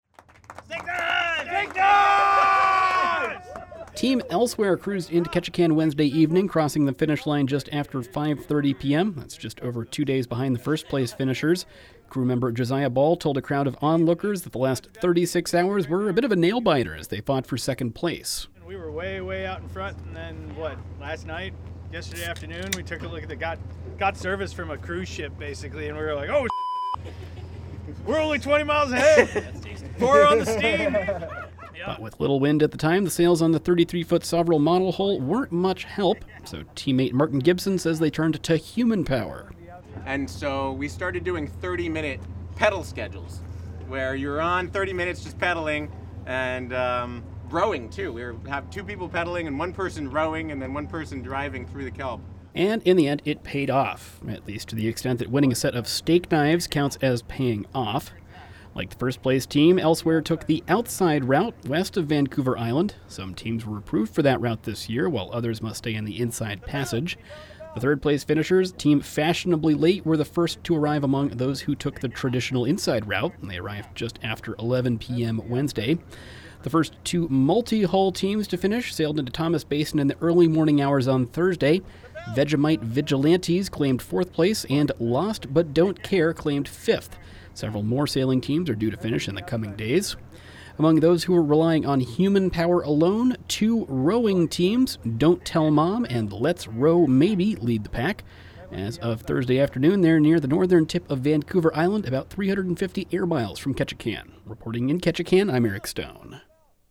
“STEAK KNIVES!” was the cry as Team Elsewhere celebrated their second-place finish in the Race to Alaska.